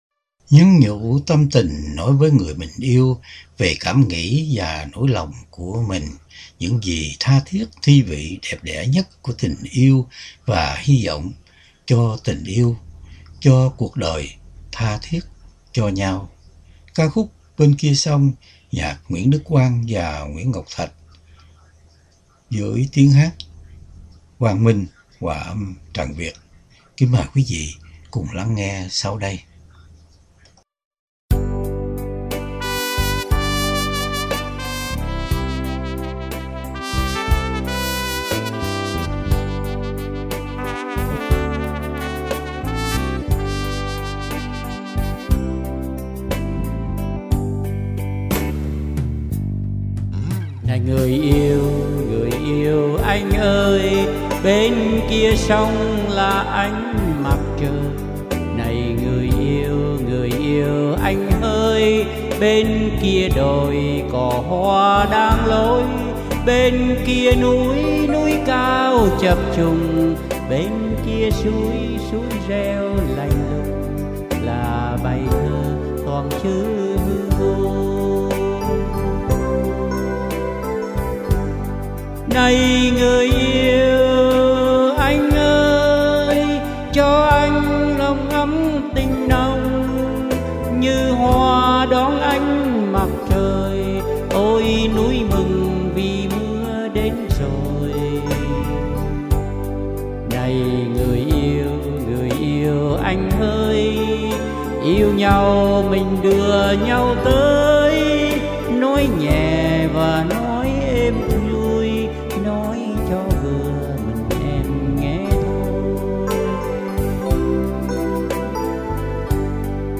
Âm Nhạc